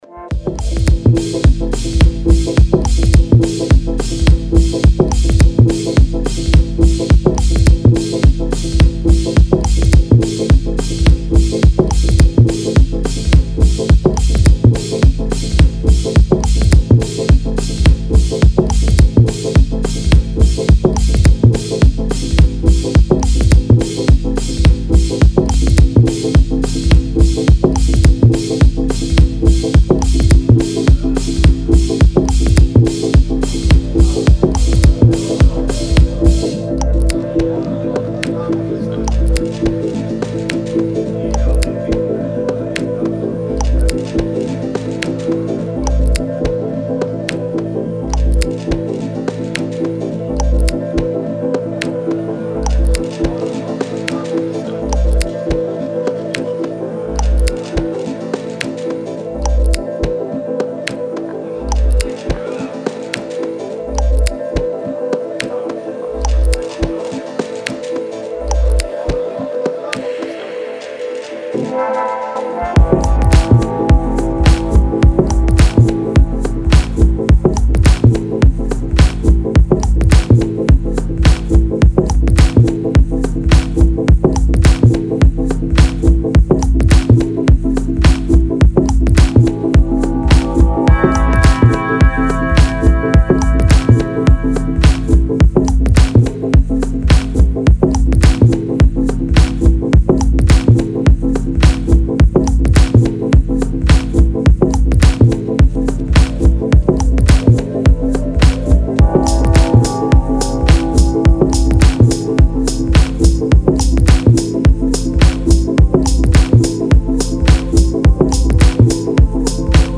Deep Tech House
Style: Deep House / Tech House